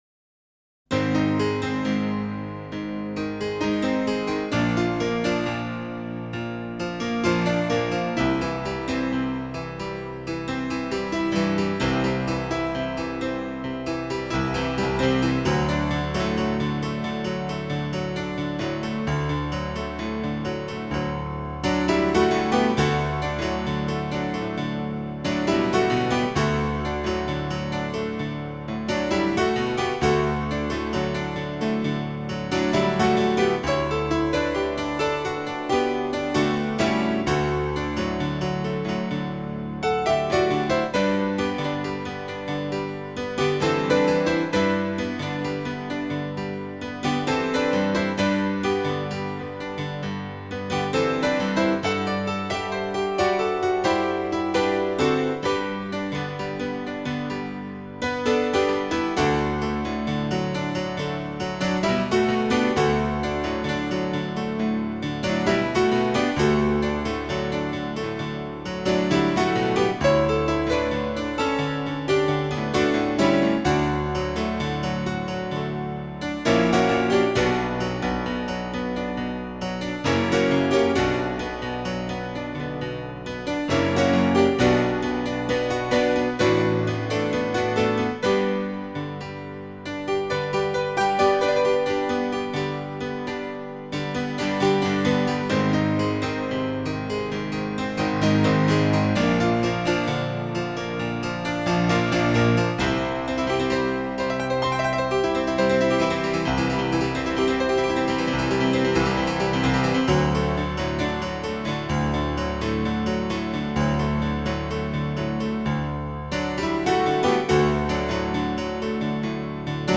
僅低音質壓縮 , 供此線上試聽
充滿寧靜 喜悅 生命力